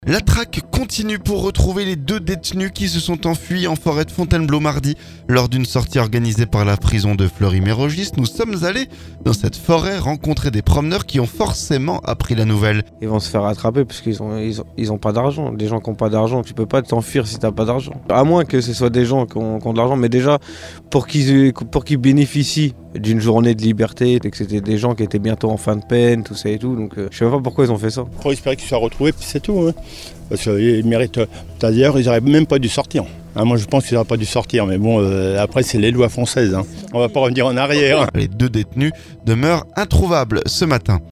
FONTAINEBLEAU - Les promeneurs réagissent aux détenus qui se sont enfuis en forêt
Nous sommes allés en forêt rencontrer des promeneurs qui ont forcément appris la nouvelle.